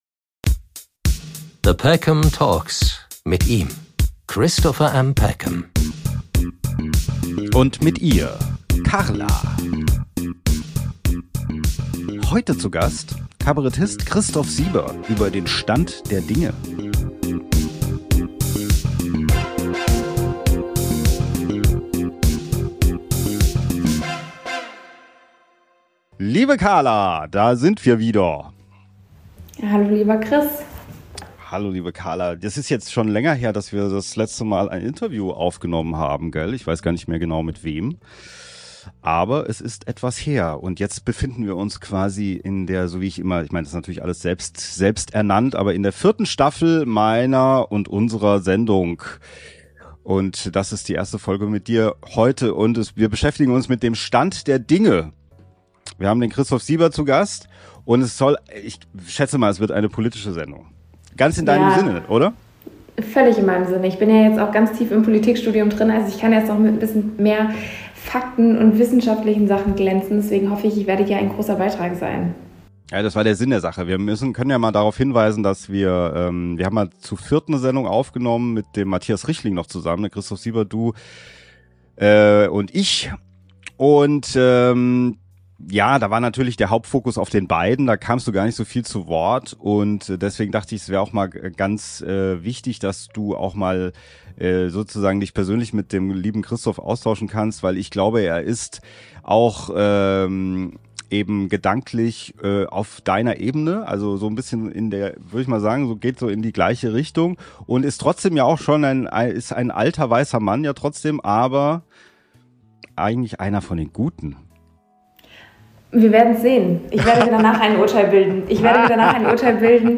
Kabarettist Christoph Sieber spricht über die aktuelle gesellschaftliche, soziale und politische Situation Deutschlands im März 2026.